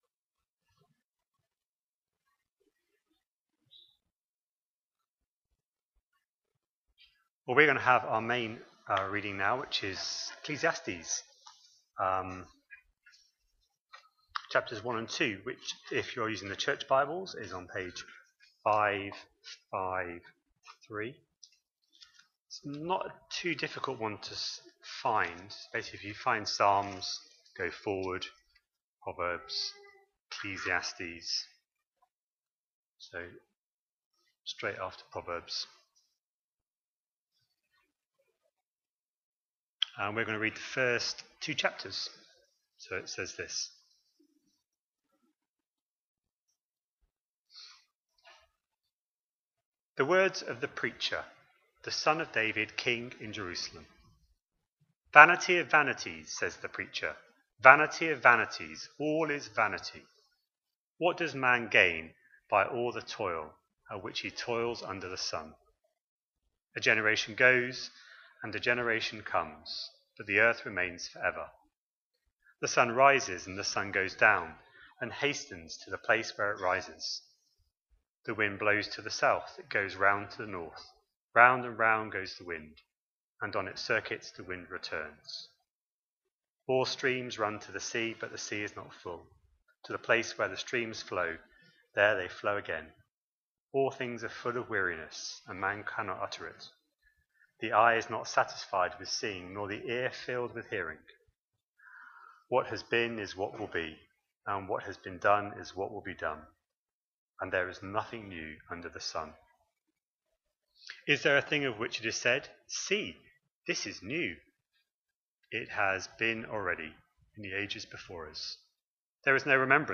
Entering God's Rest (Hebrews 4:1-13) Sermons From Trinity Church Bradford podcast